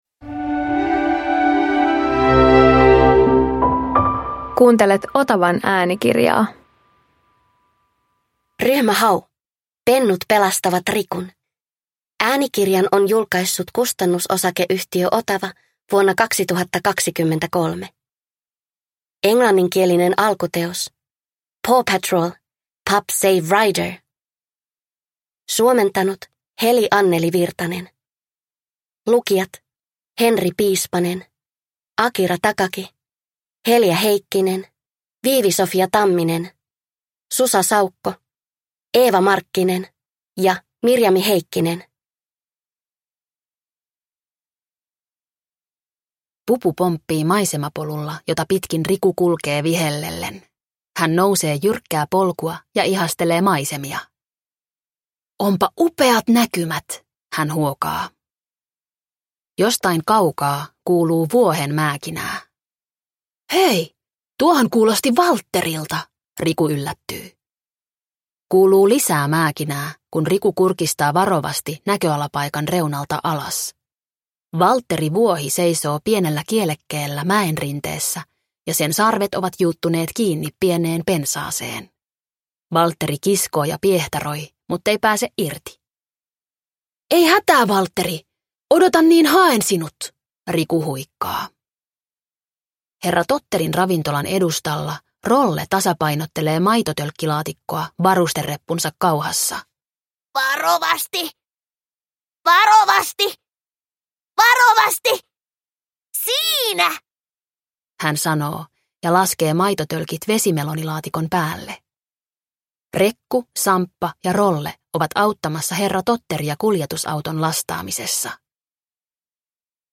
Ryhmä Hau - Pennut pelastavat Rikun – Ljudbok